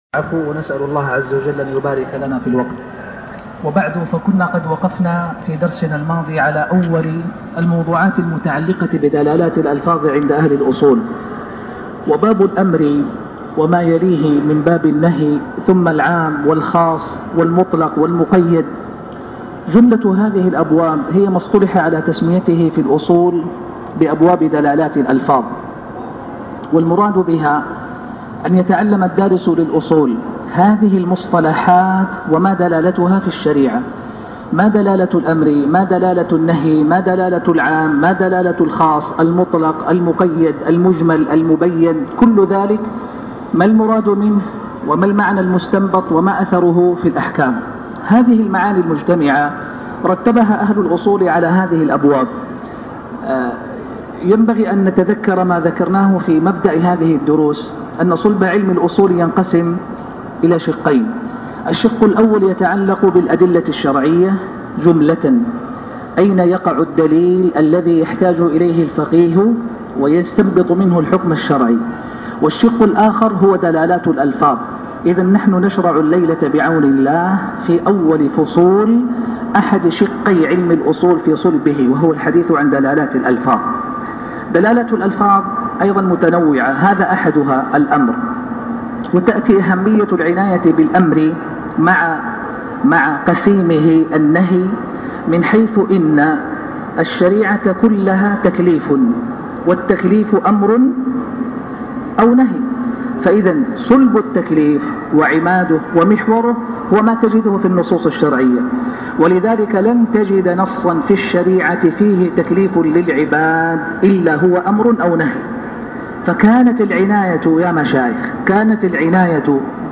الدرس الرابع من شرح (الاصول من علم الاصول )